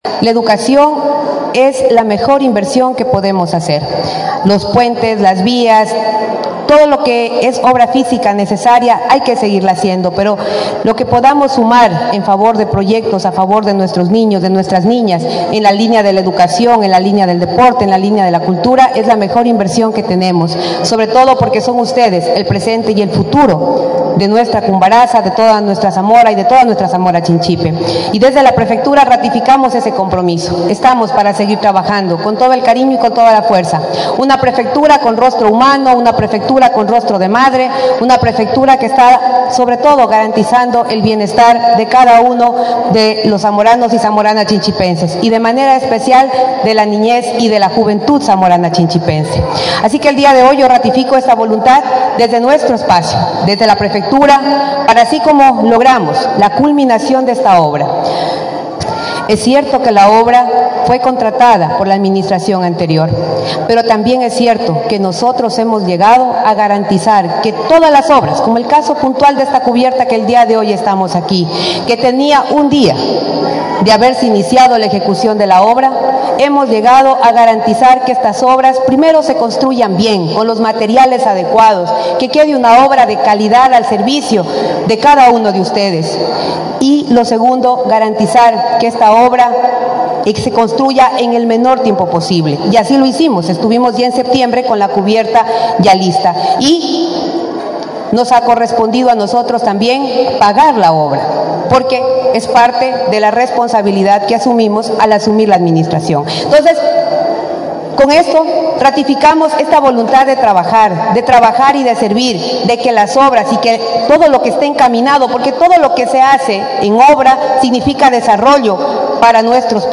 Con mucha alegría y emoción, la mañana de este jueves 16 de mayo, la Unidad Educativa “Río Zamora» de la parroquia Cumbaratza, cantón Zamora, recibió formalmente la cubierta de estructura metálica y reconstrucción de la cancha de uso múltiple en este plantel.
KARLA REÁTEGUI, PREFECTA